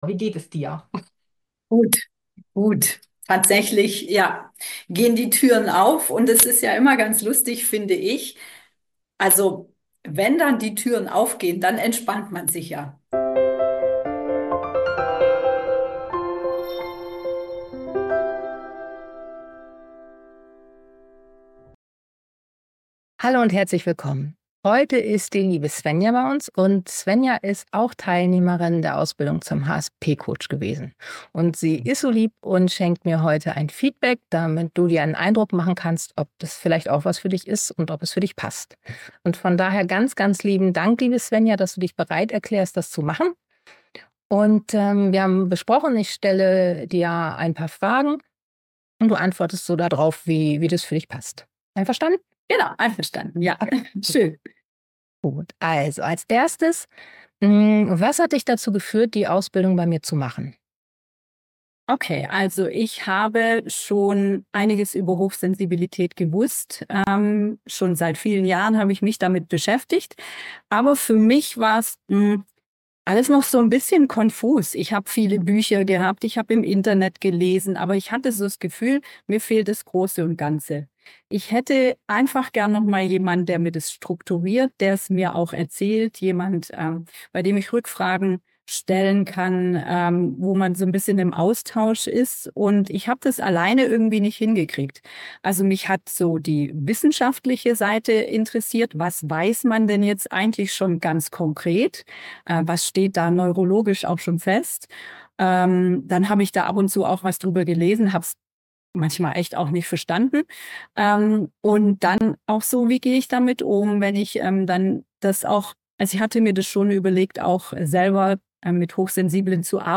Eine Teilnehmerstimme aus der Ausbildung